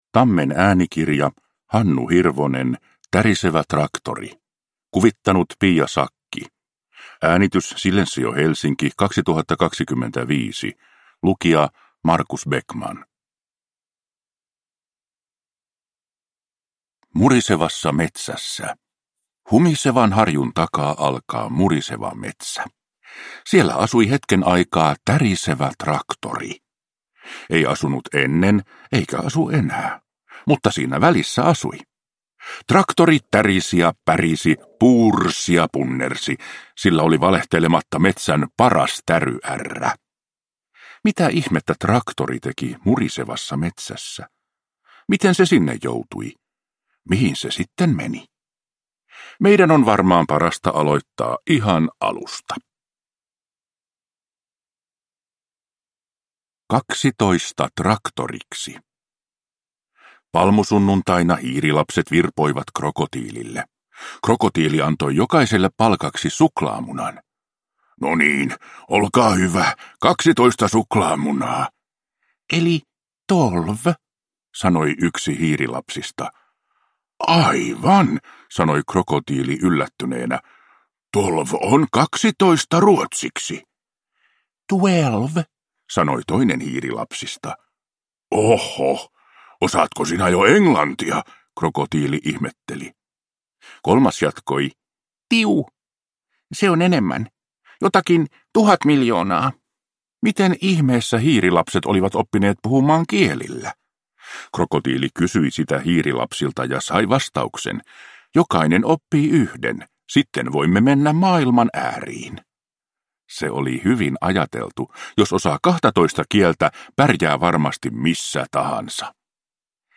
Tärisevä traktori – Ljudbok